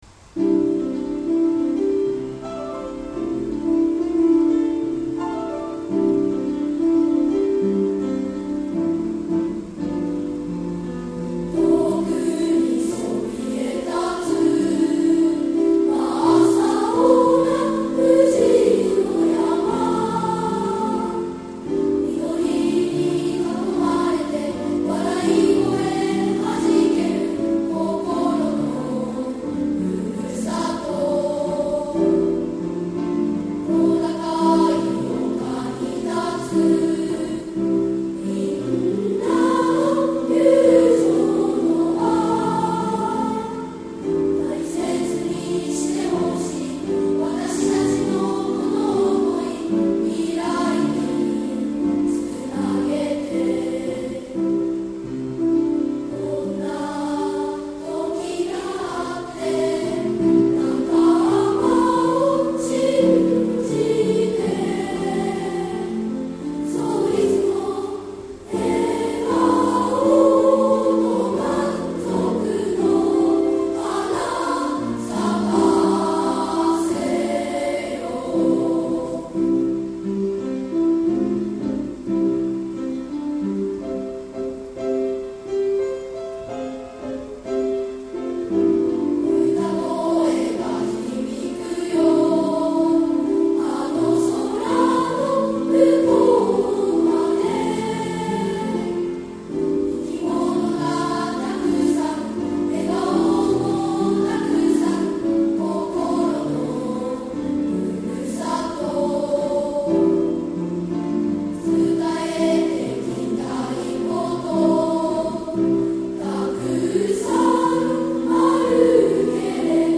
７月２日（月）、音楽朝会で６年生が発表しました。曲は創立５０周年記念歌「Hope」です。